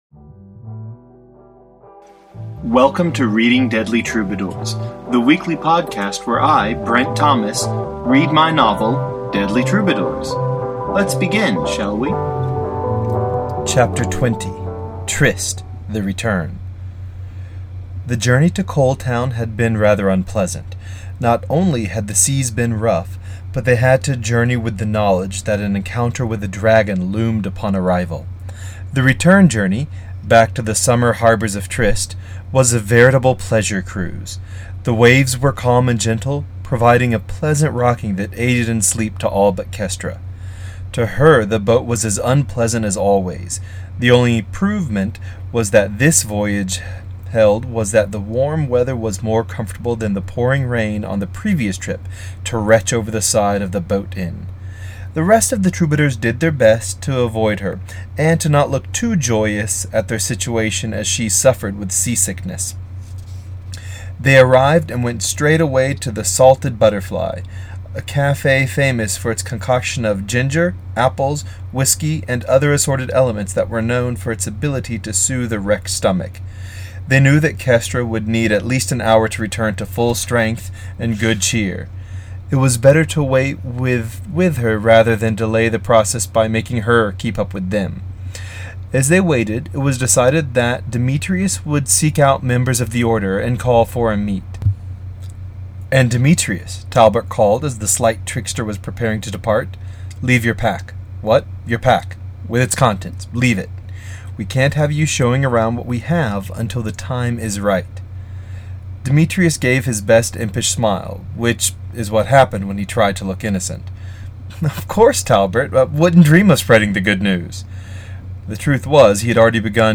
reads the twentieth chapter of Deadly Troubadours